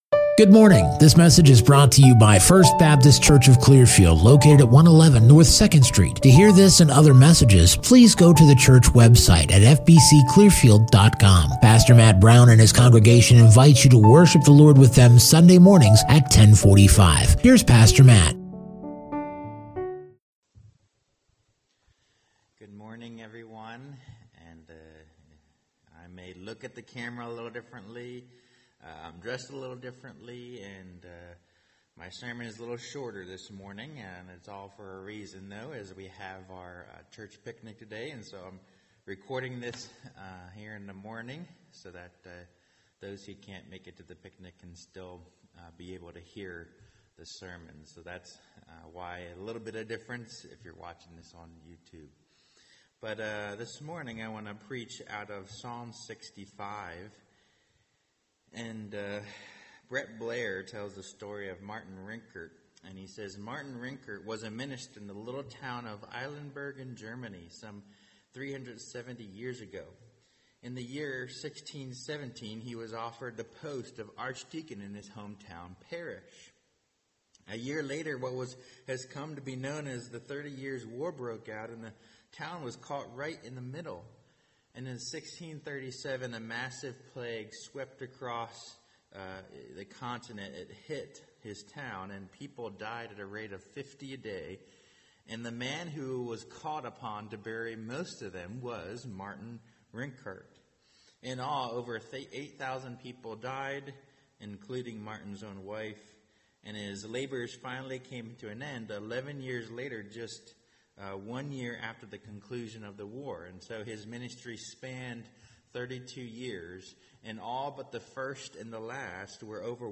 Non-Series Sermon Passage